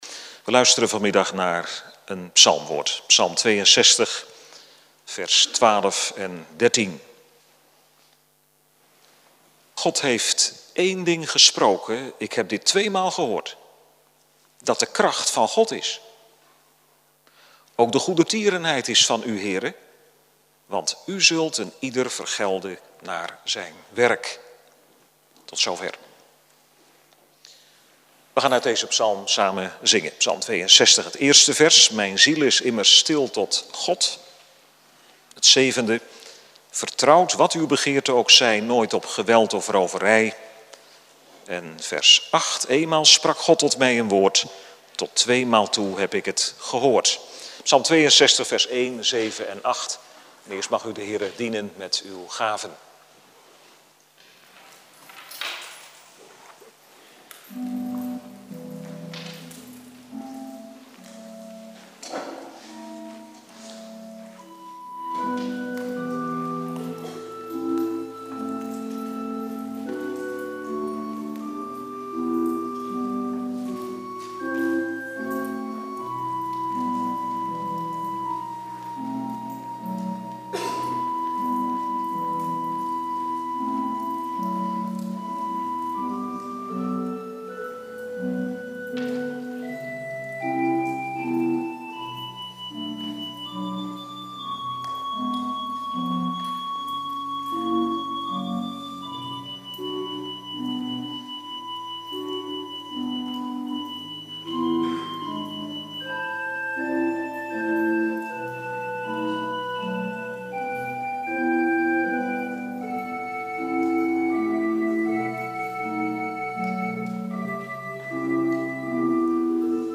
Beste lezer, Als antwoord op deze vraag dit keer een gedeelte van een preek ( download ) die ik over deze tekst hield.